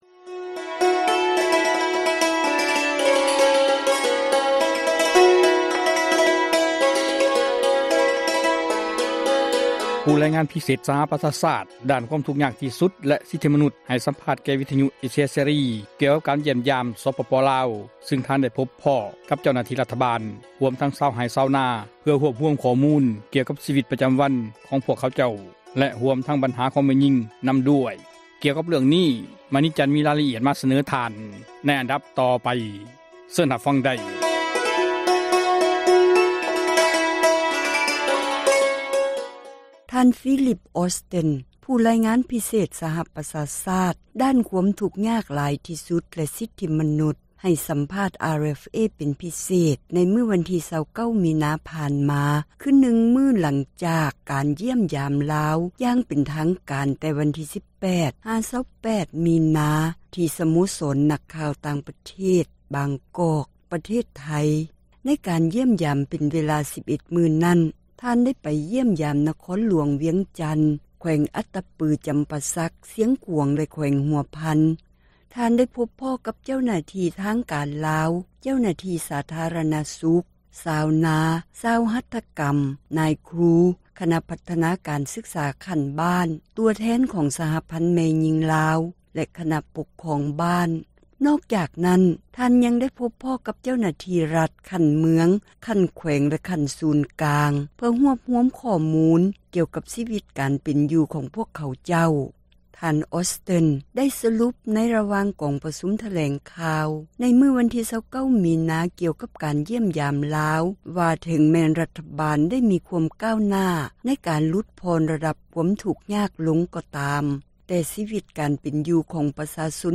ຜູ້ຣາຍງານ ພິເສດ ສະຫະປະຊາຊາດ ດ້ານ ຄວາມທຸກຍາກ ທີ່ສຸດ ແລະ ສິດທິມະນຸດ ໃຫ້ ສັມພາດ RFA ກ່ຽວກັບ ການຢ້ຽມຢາມ ລາວ ຊຶ່ງ ທ່ານ ໄດ້ພົບພໍ້ ກັບ ເຈົ້າໜ້າທີ່ ຣັຖບານ ຮວມເຖິງ ຊາວໄຮ່ ຊາວນາ.